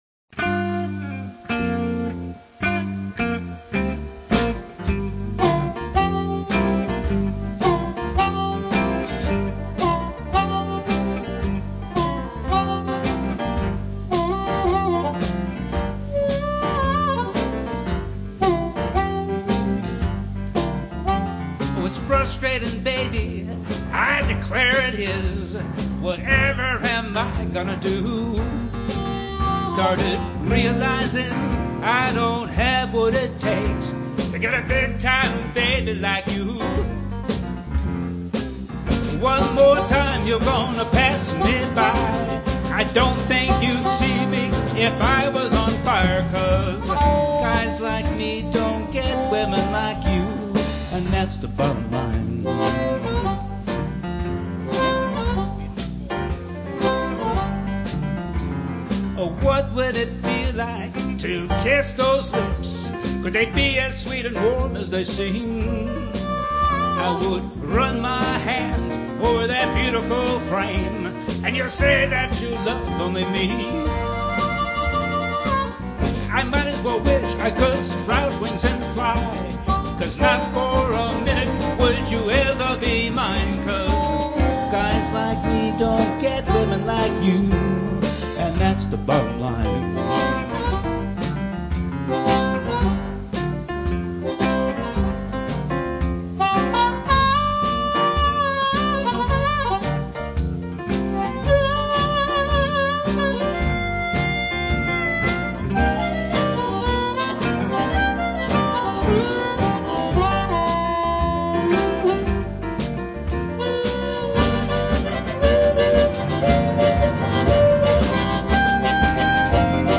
blues band
guitar, vocals
harmonica, vocals
keyboards
drums.